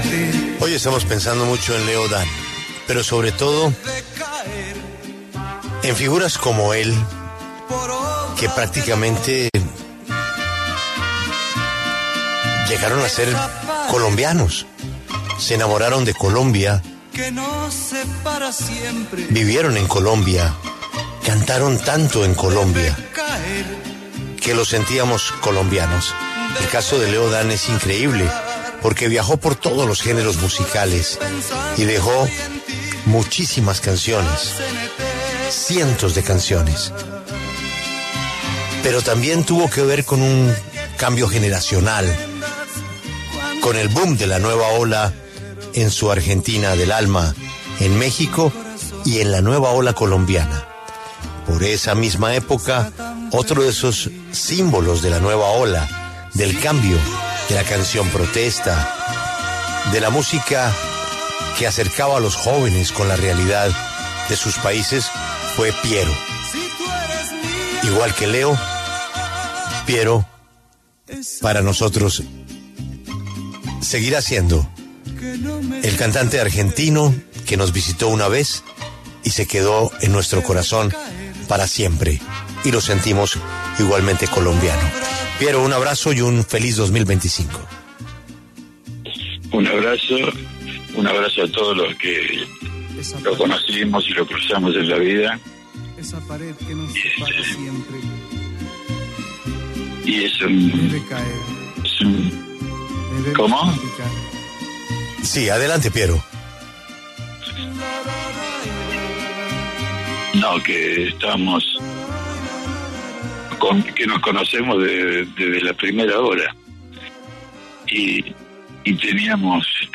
Piero, cantante colombiano, pasó por los micrófonos de La W y lamentó la muerte de también compositor argentino.